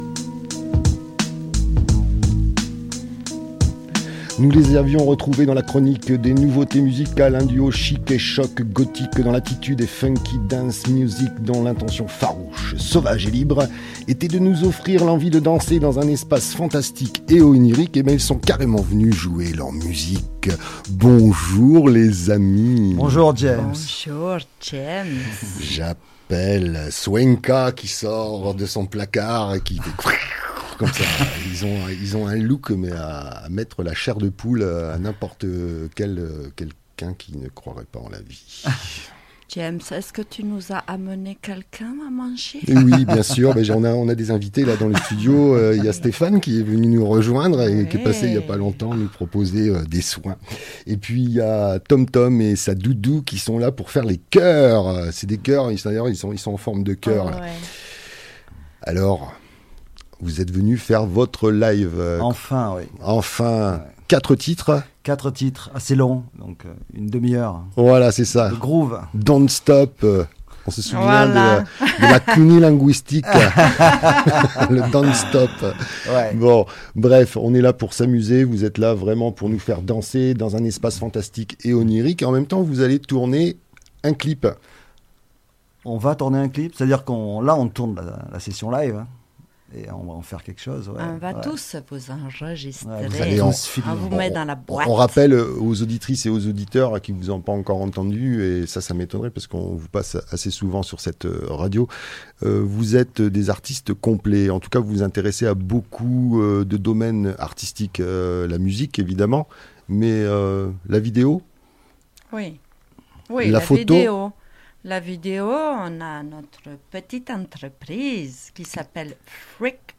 Music Funk Gothic and dance